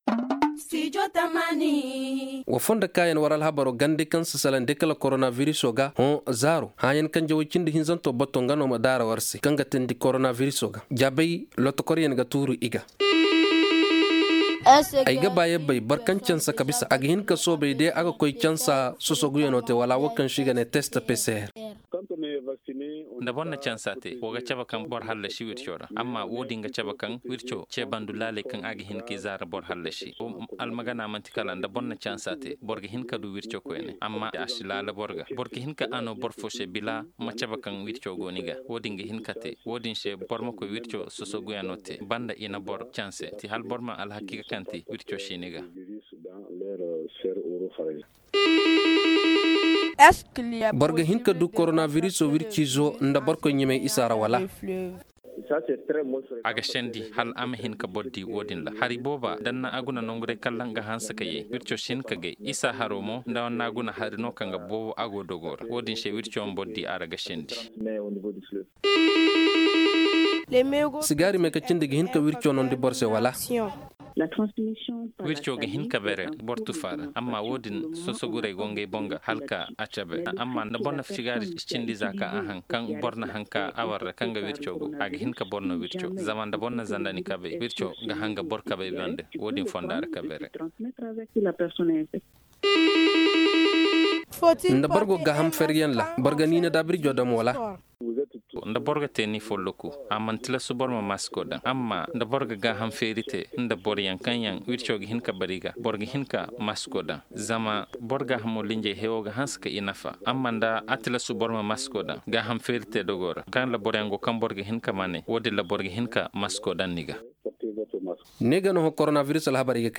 Aujourd’hui, nous vous proposons des questions posées par des auditeurs. Elles sont répondues par des spécialistes de la santé.Suivons!